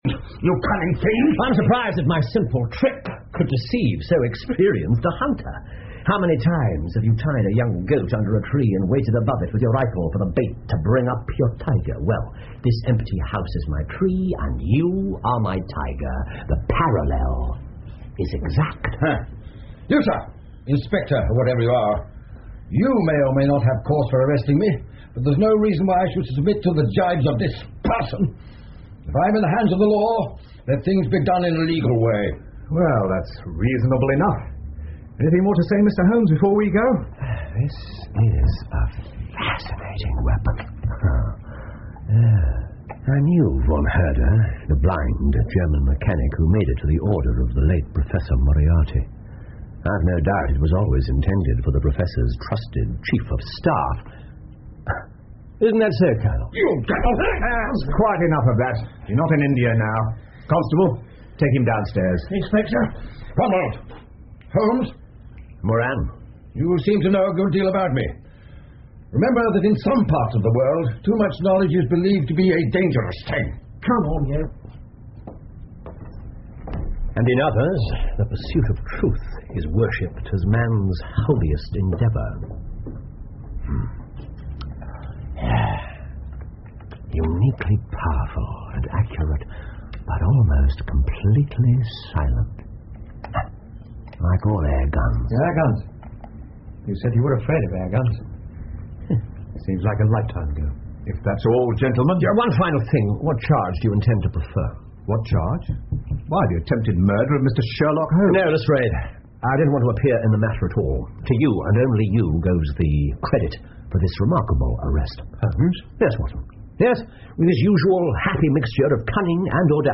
福尔摩斯广播剧 The Empty House 8 听力文件下载—在线英语听力室
在线英语听力室福尔摩斯广播剧 The Empty House 8的听力文件下载,英语有声读物,英文广播剧-在线英语听力室